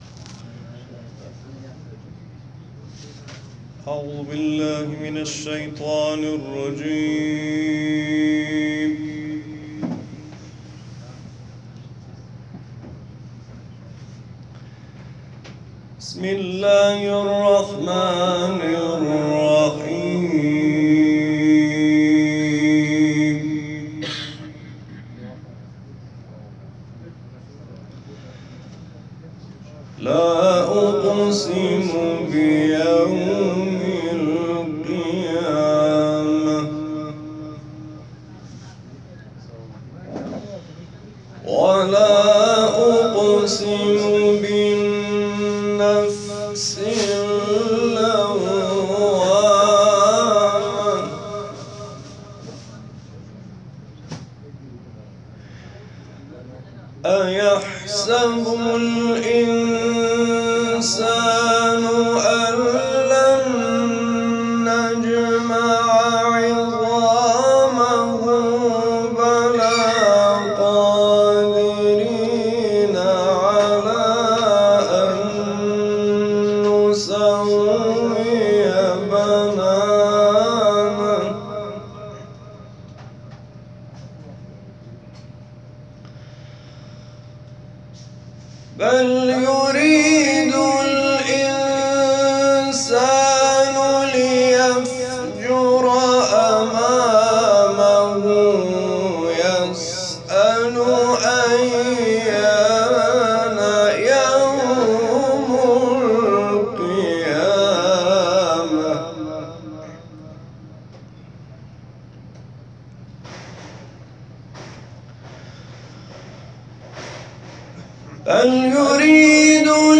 7 سوره قیامه   /   مدینه  - حج 98